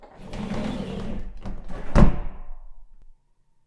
風呂スライドドア閉
cl_alum_BR_door1.mp3